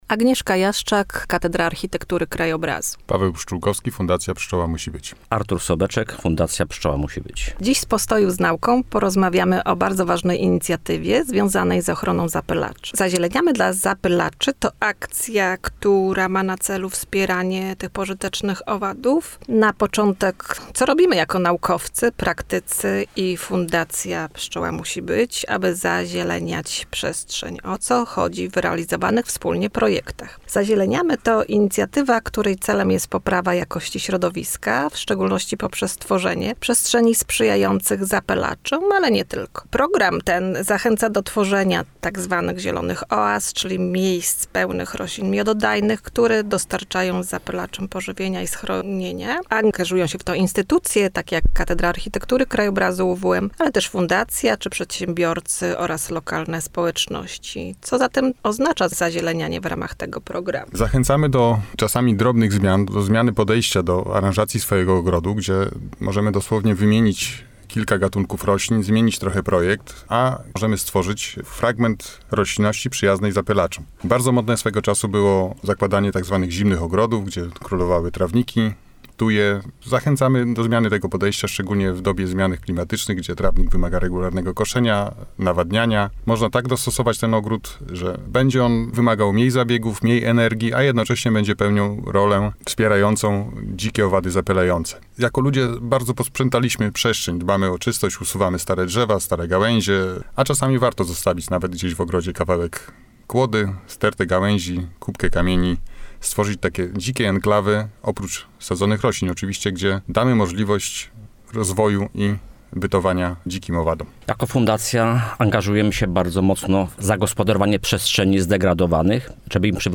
A w studiu Radia UWM FM pojawiła się z przedstawicielami  Fundacji Pszczoła Musi Być.